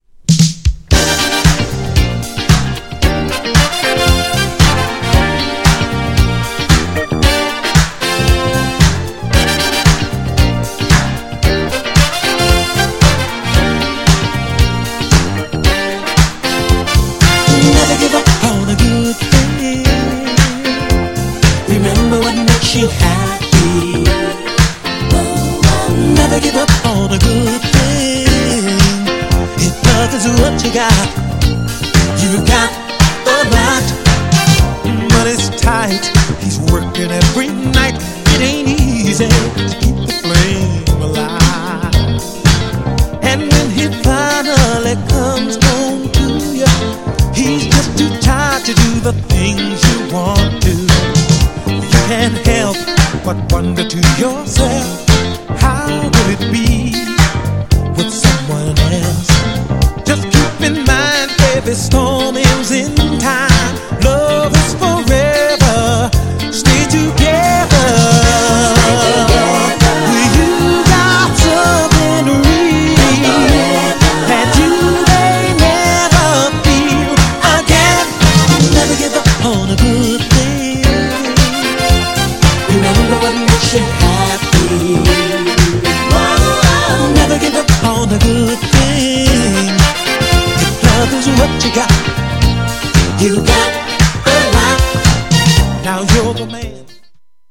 GENRE Dance Classic
BPM 76〜80BPM